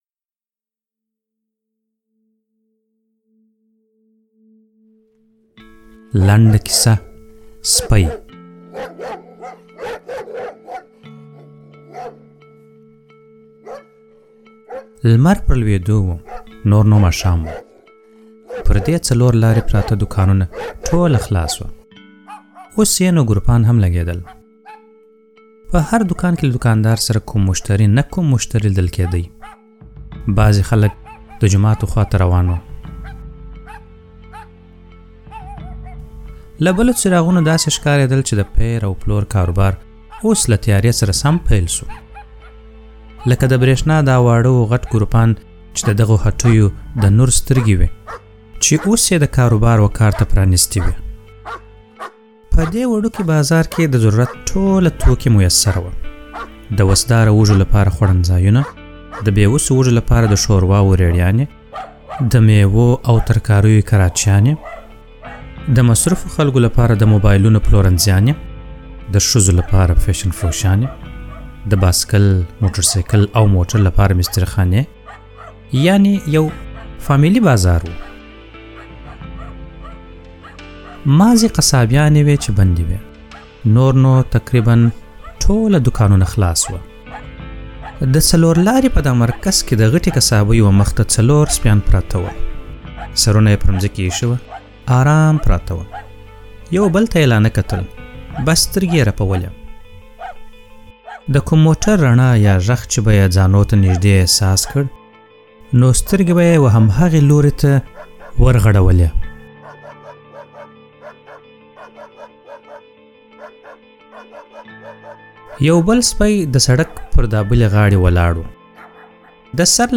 ږغیزي لنډي کیسې